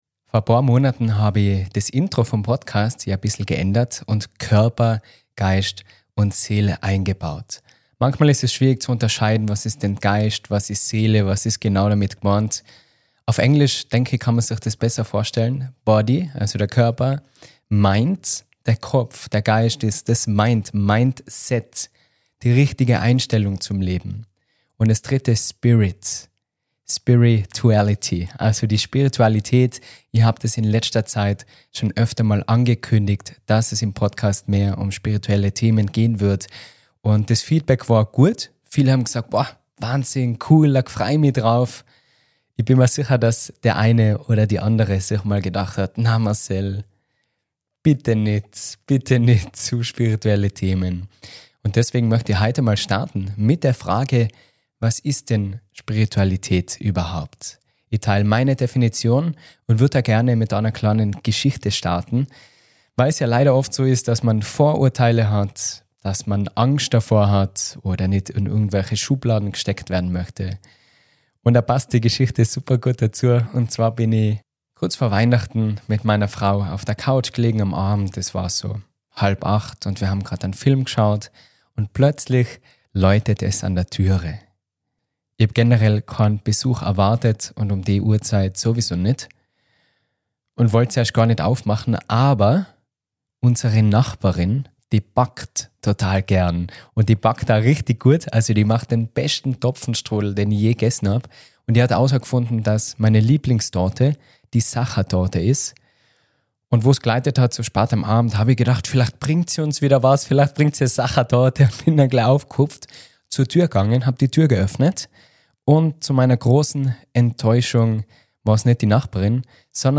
1 🧘‍♀ Future Self Meditation aus meinem Glow Up Workshop (#287) 28:21